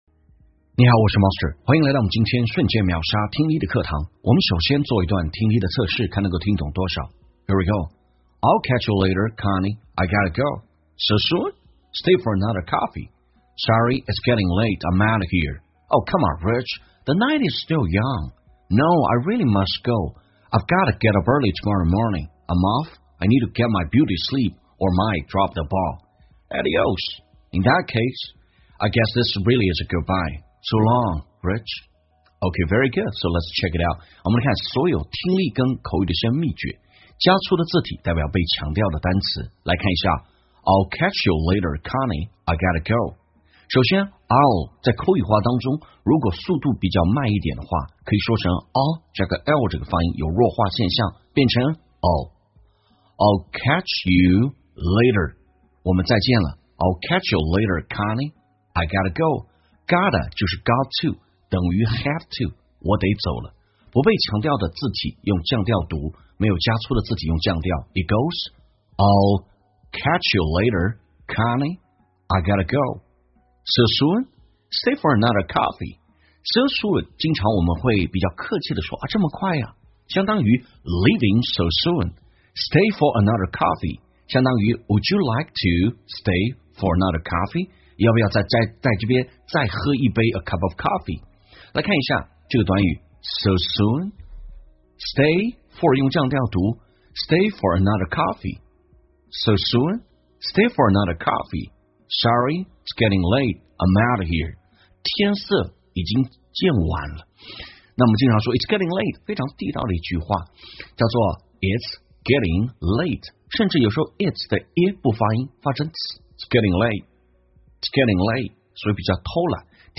在线英语听力室瞬间秒杀听力 第666期:Beauty Sleep美容觉的听力文件下载,栏目通过对几个小短句的断句停顿、语音语调连读分析，帮你掌握地道英语的发音特点，让你的朗读更流畅自然。